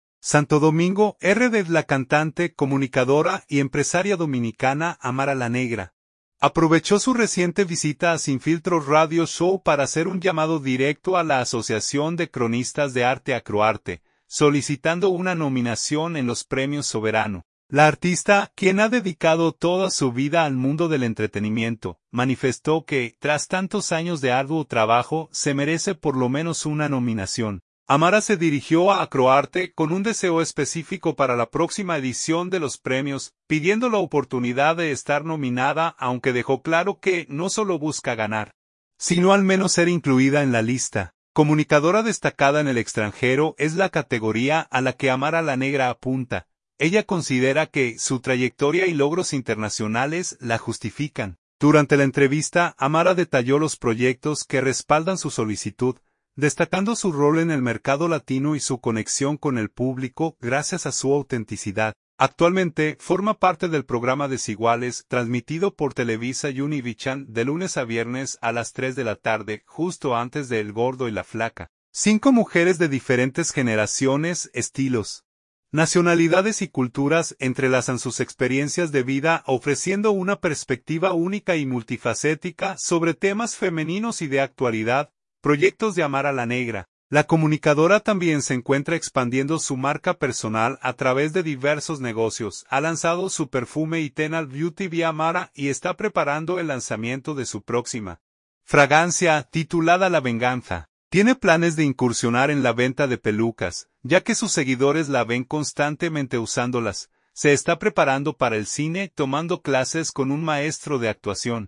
Santo Domingo, RD. – La cantante, comunicadora y empresaria dominicana, Amara La Negra, aprovechó su reciente visita a Sin Filtro Radio Show para hacer un llamado directo a la Asociación de Cronistas de Arte (Acroarte), solicitando una nominación en los Premios Soberano.